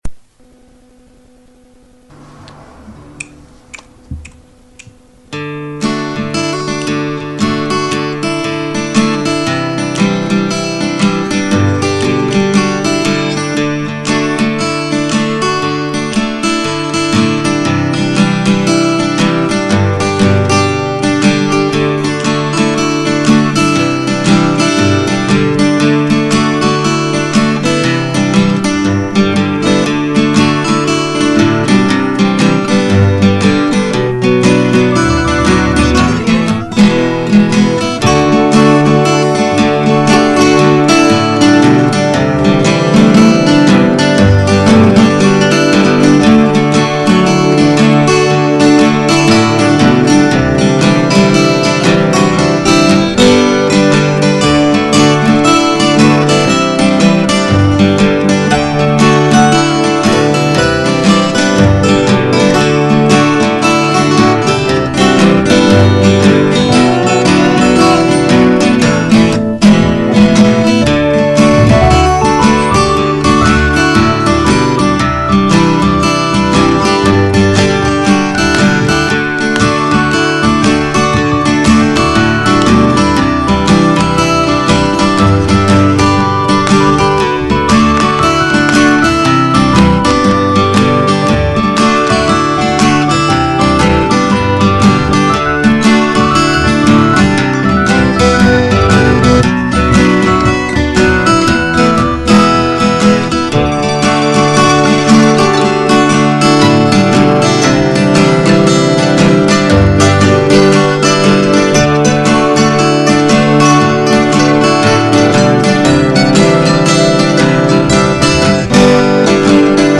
Instrumentals
That feeling in the air just before someone suggests tea. Mandolin debut.